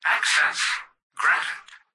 "Access granted" excerpt of the reversed speech found in the Halo 3 Terminals.